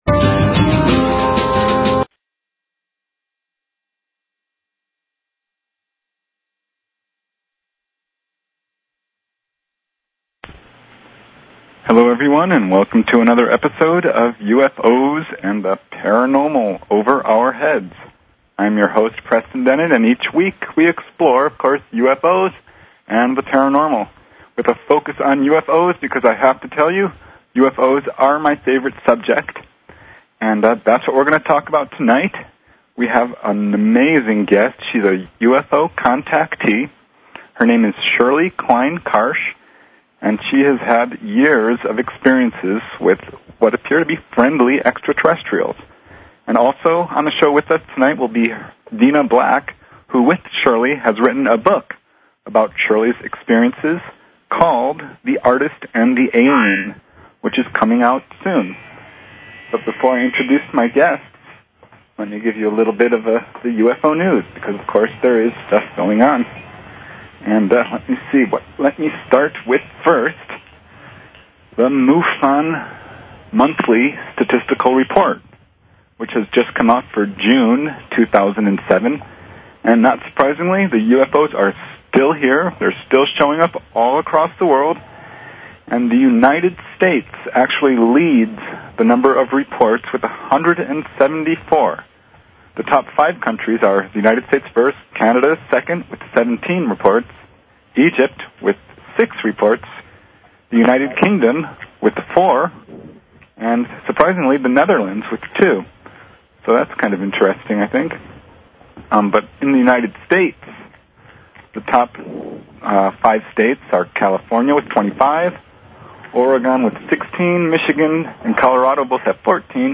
Talk Show Episode, Audio Podcast, UFOs_and_the_Paranormal and Courtesy of BBS Radio on , show guests , about , categorized as